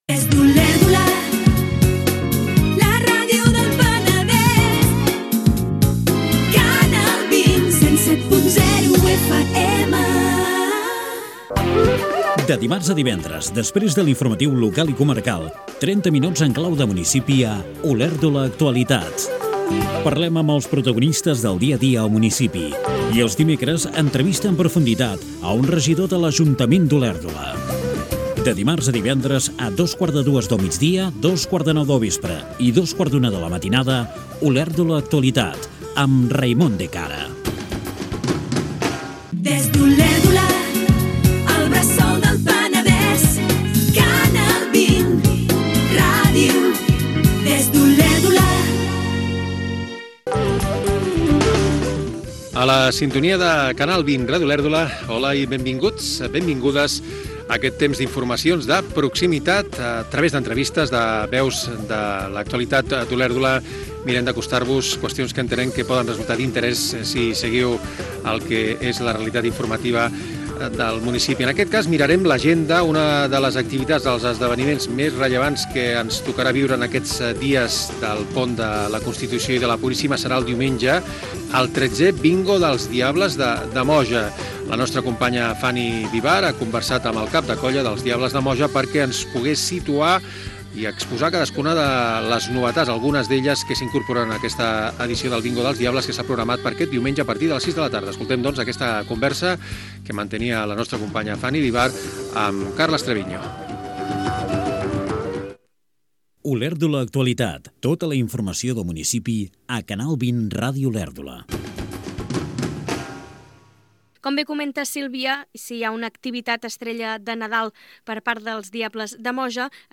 Indicatiu emissora. Careta del programa. Fragment d'un reportatge sobre la colla "Els diables de Moja".
Informatiu